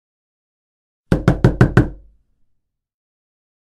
Door knock ..
knocking-sound-effect.mp3